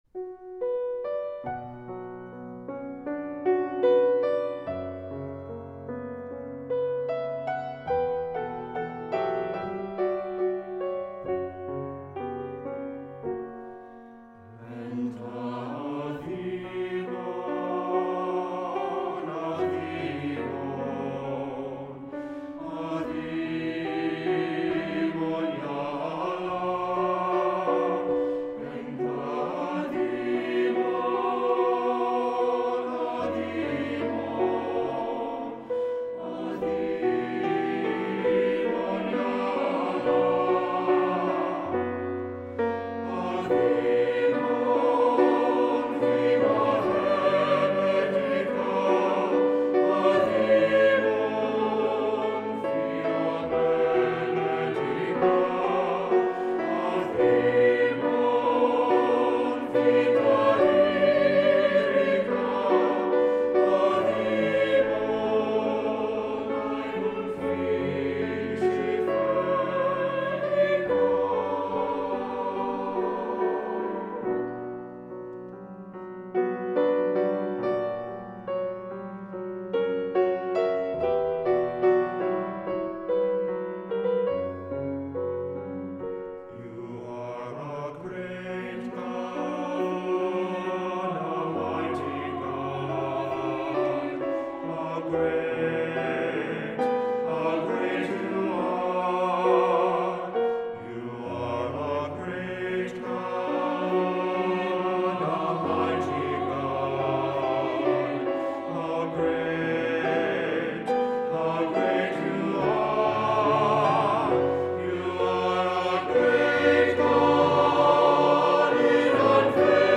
Voicing: SAB; SATB